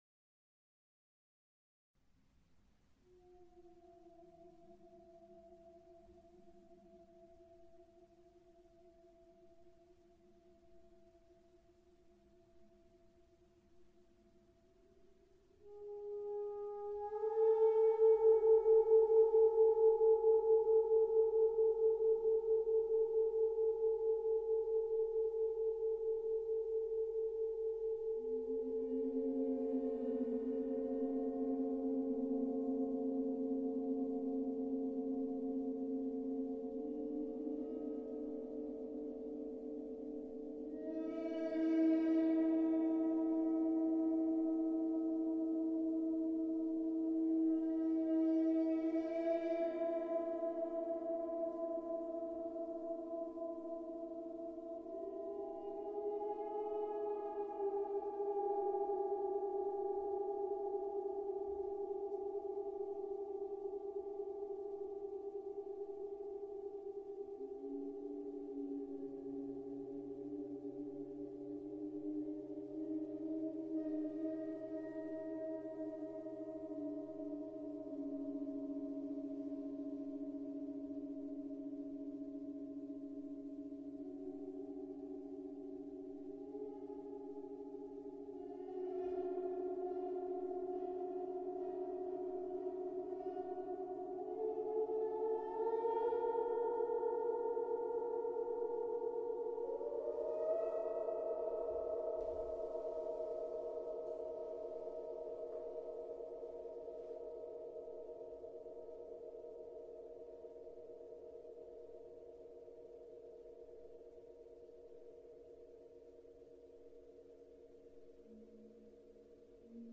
New A 47" Titanium Gong sound effects free download
New A 47" Titanium Gong called "Reverence"